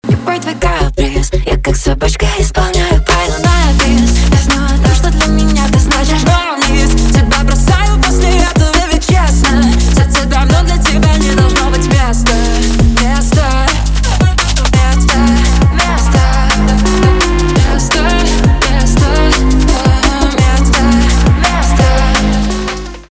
электроника
грустные , битовые , басы